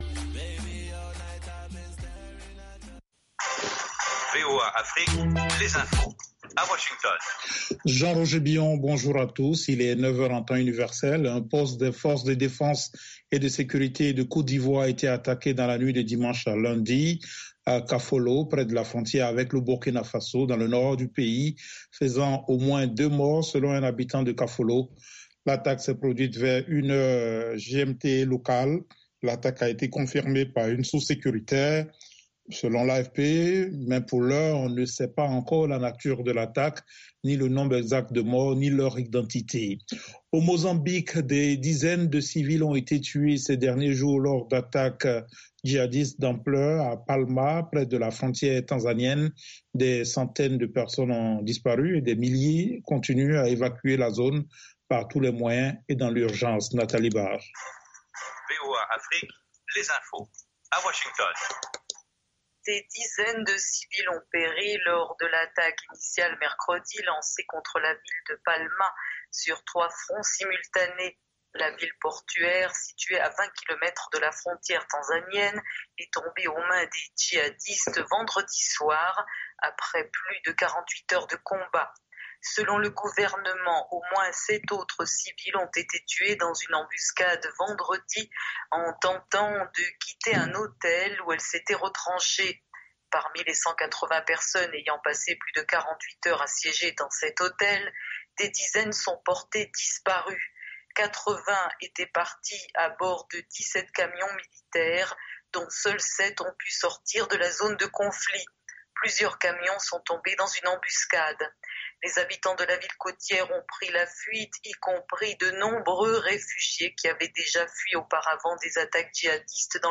Bulletin
5 min News French